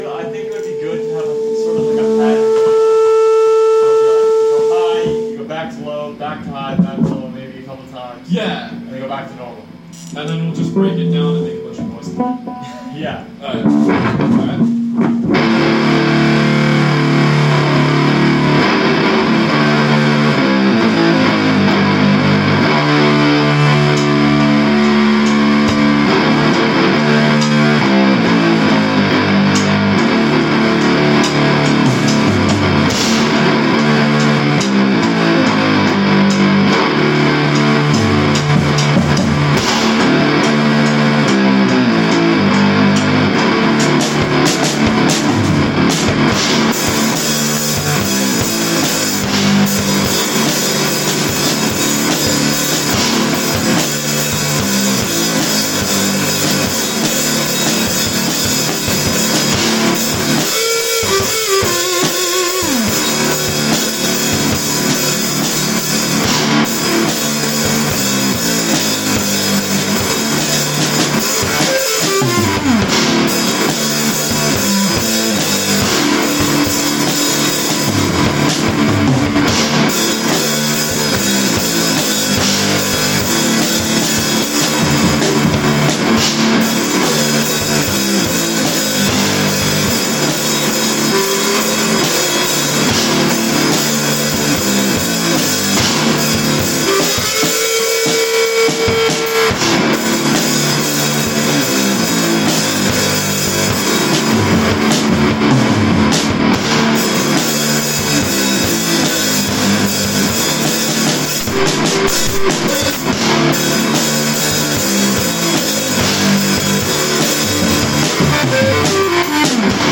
jul2022sludgejam.mp3